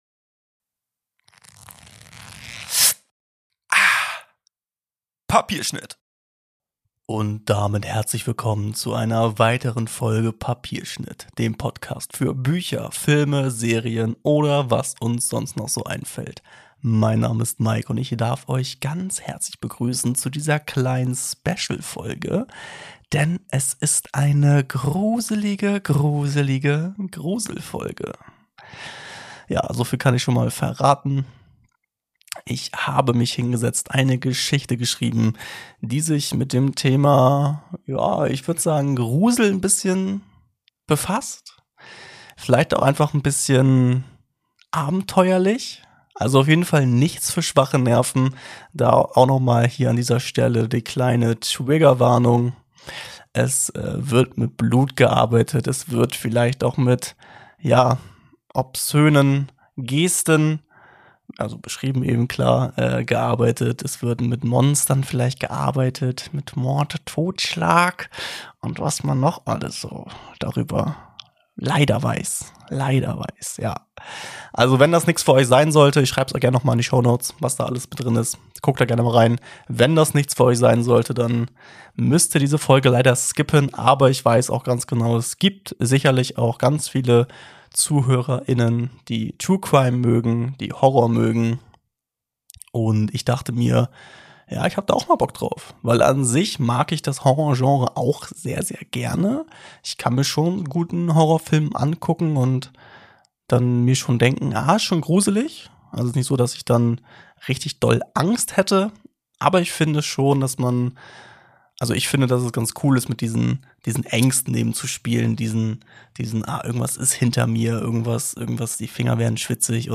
Triggerwarnungen / Hinweise: In dieser Folge werden Themen wie: Gewalthandlungen, verstörende Musik / Effects, Horrorelemente.